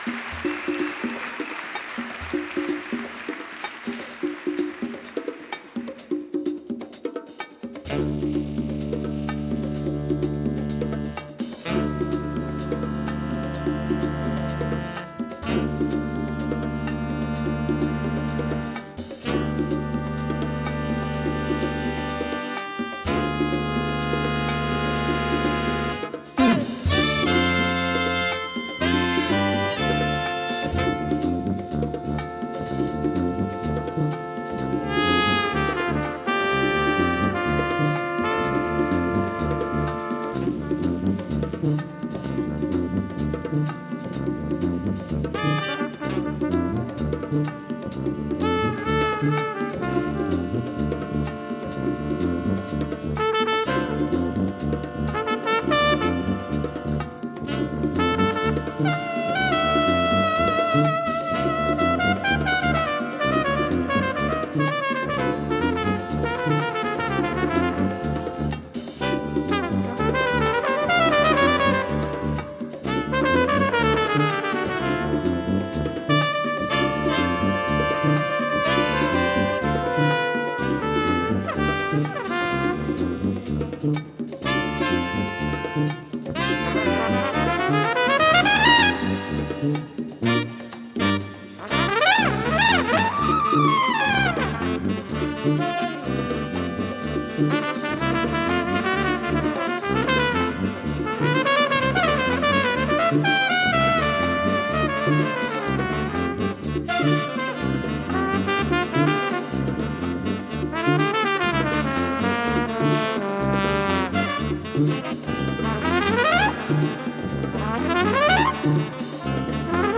Тип альбома: Студийный
Жанр: Post-Bop
trumpet